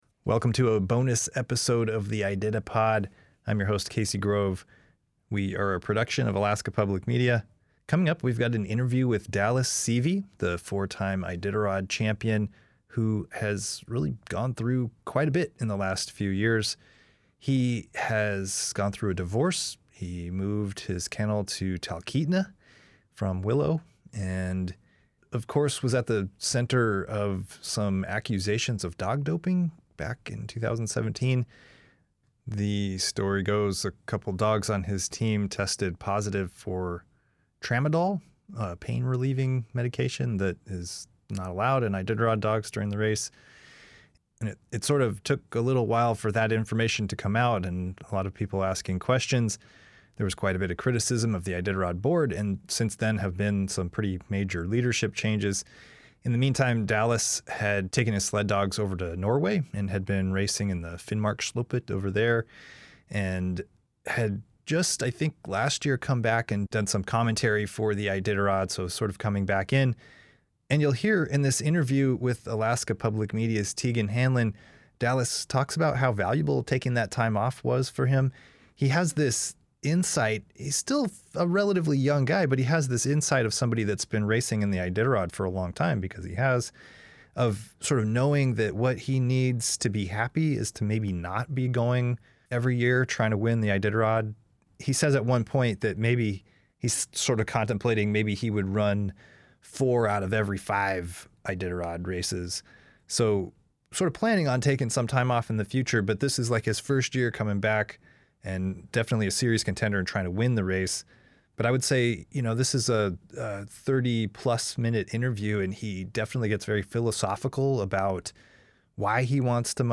Talkeetna interview with Dallas Seavey